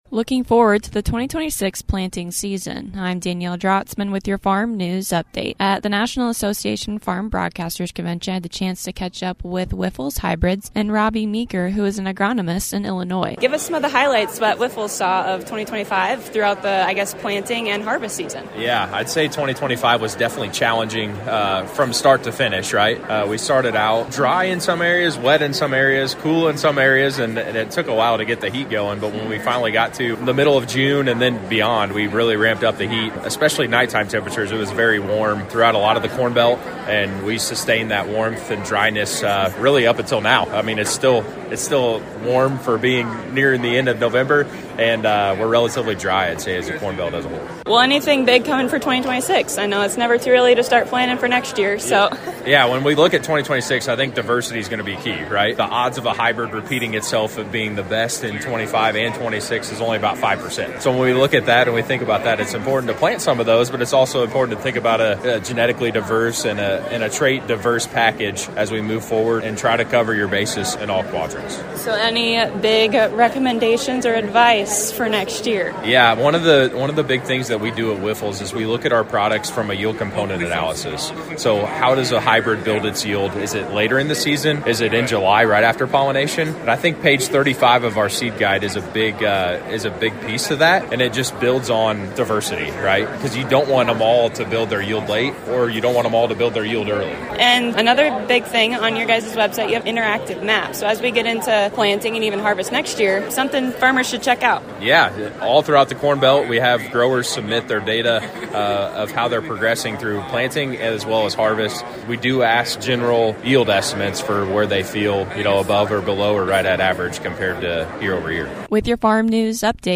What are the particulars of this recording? Advice for the 2026 crop with Wyffels Hybrids from the NAFB Convention in Kansas City!